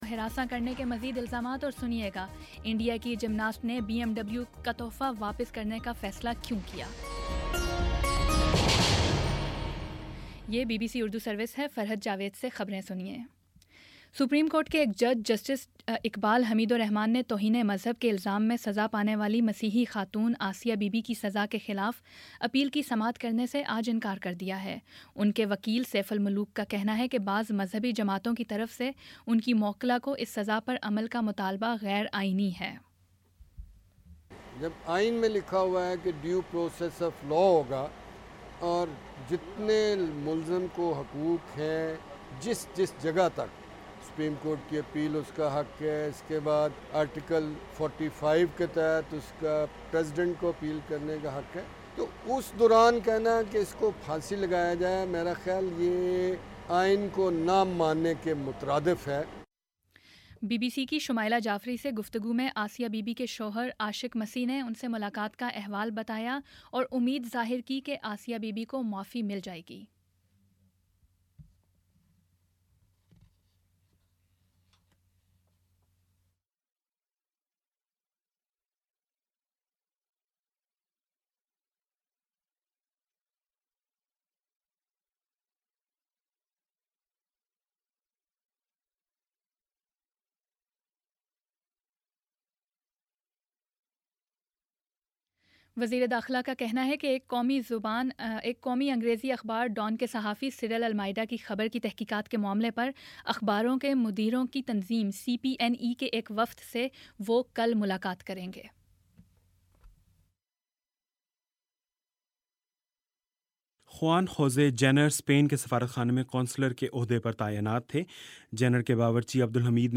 اکتوبر13 : شام سات بجے کا نیوز بُلیٹن